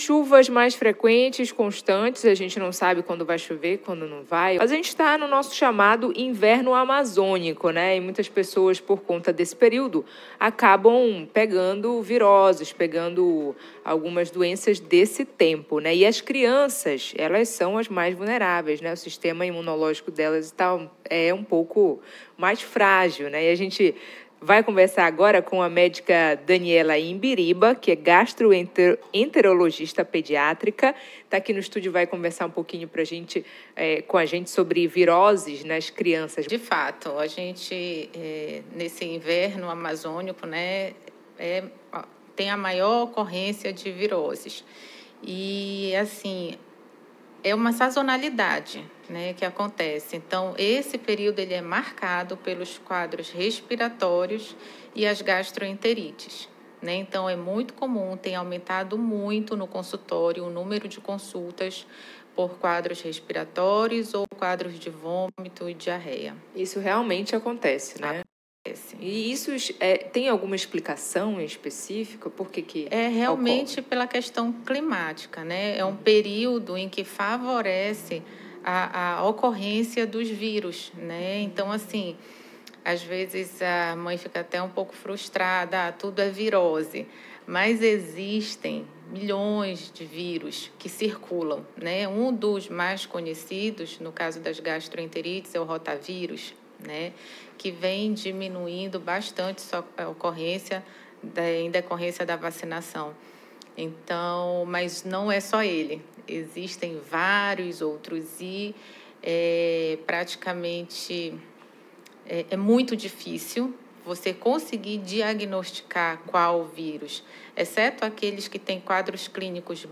Gripes, viroses e doenças gastrointestinais são mais comuns neste período. Acompanhe a entrevista
GASTROPEDIATRA-SOBRE-DOENAS-INVERNO.mp3